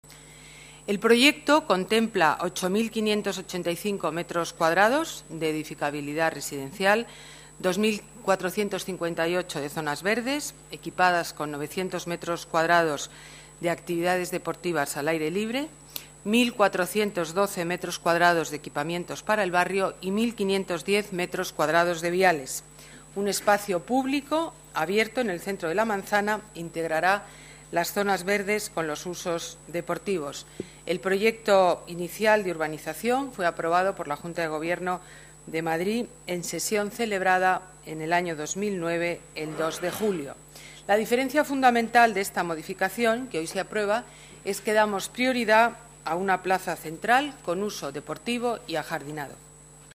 Nueva ventana:Declaraciones alcaldesa Madrid, Ana Botella: viviendas, zonas verdes y deportivas para las Cocheras de Bravo Murillo